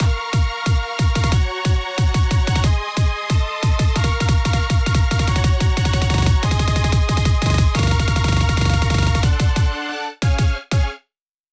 Hardcore
ここもリズムが分かりやすいようにキック付きです。
ロールうるッせぇ
ここがアウトロのキモ、裏コードです。